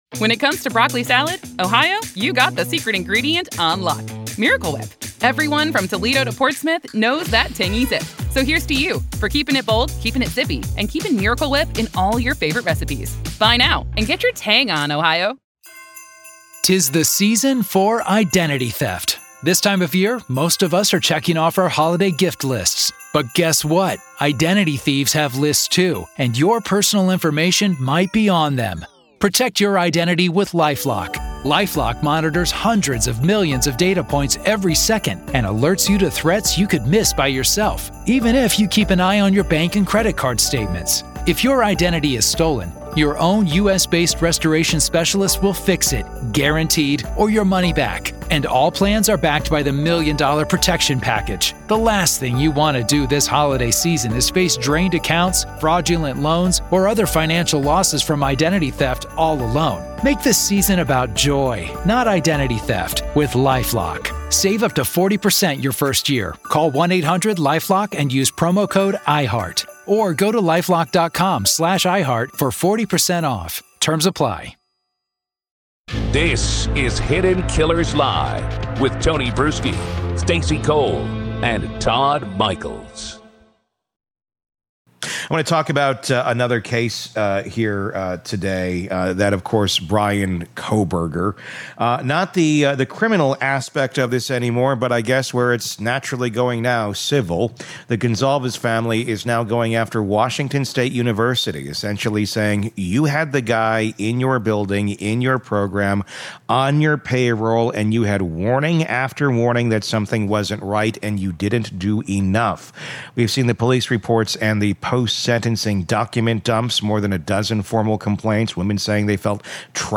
True Crime Today | Daily True Crime News & Interviews / WSU in the Hot Seat — Did They Ignore the Warnings About Kohberger?